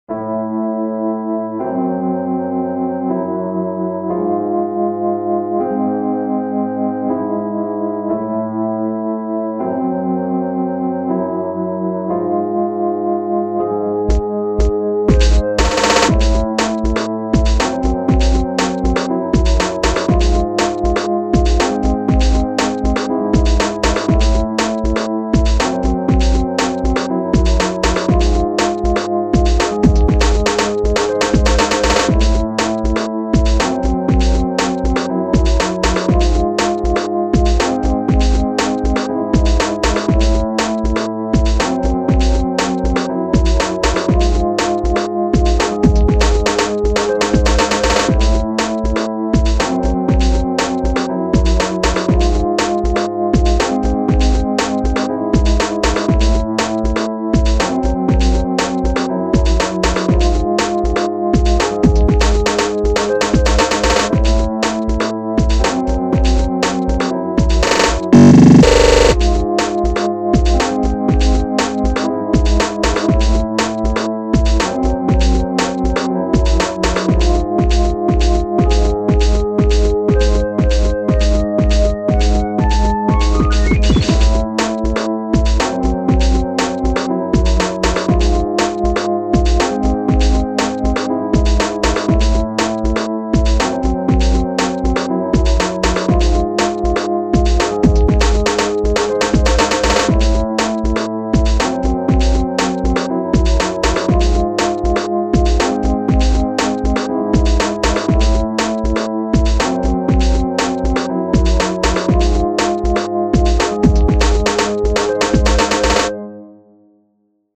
сыро и плоско, как коврик в ванной.
ещё и рвано, как будто коврик пытались засунуть в бельевую корзину.